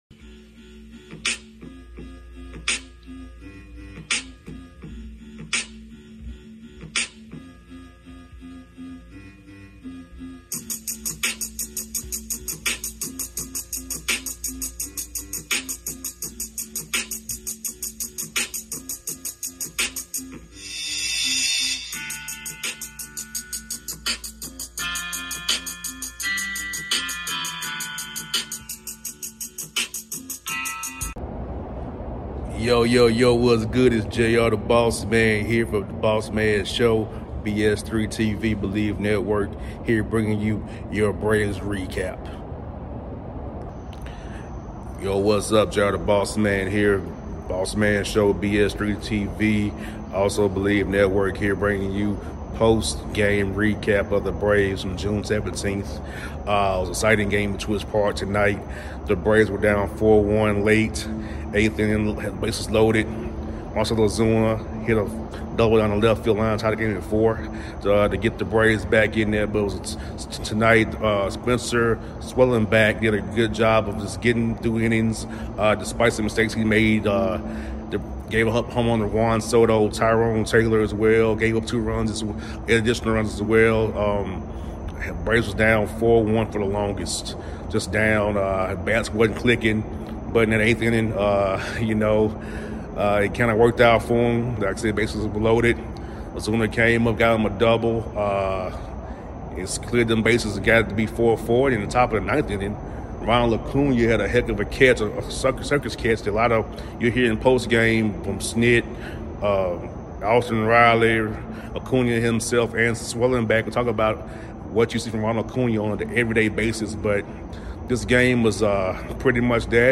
Braves win 5-4 over to the New York Mets at Truist Park in 10 innings. W: Iglesias (4-5) L: Brazobán (3-2) In addition to my thoughts on the game hear postgame comments from Pitcher Spencer Schwellenbach, Right Fielder Ronald Acuña Jr., Manager Brian Snitker and 3rd Baseman Austin Riley.